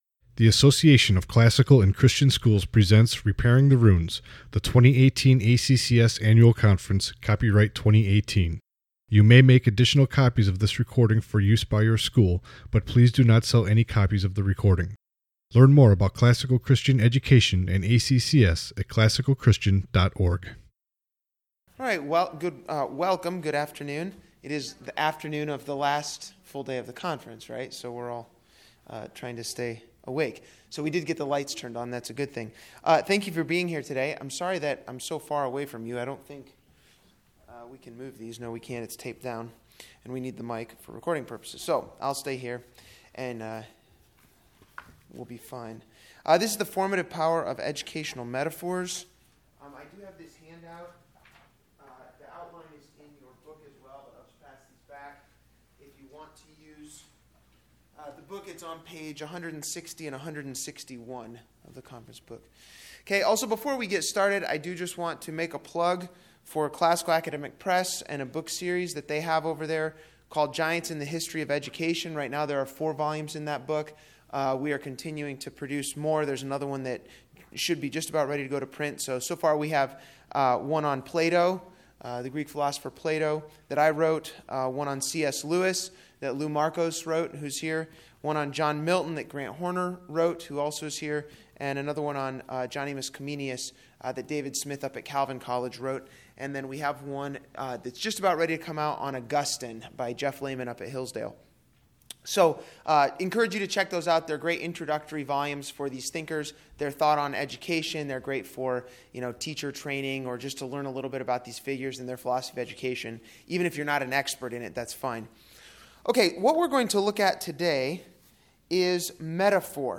2018 Workshop Talk | 54:14 | All Grade Levels, General Classroom